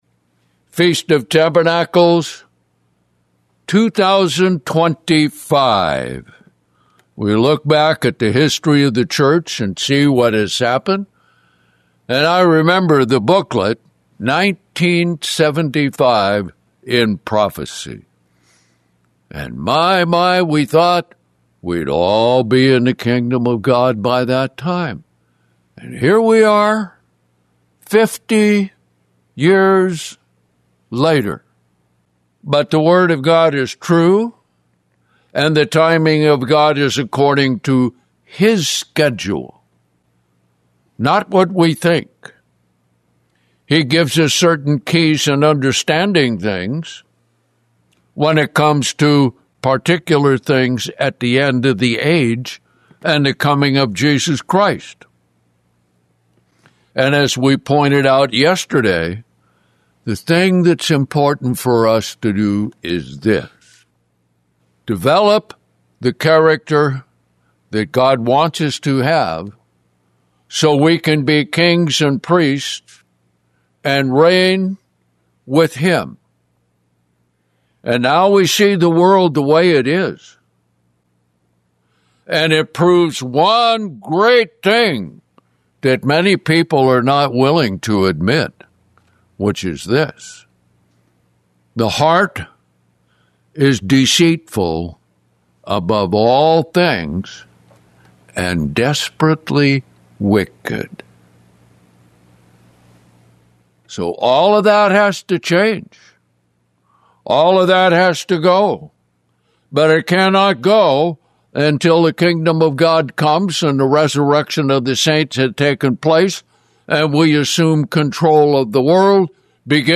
(FOT Day 5)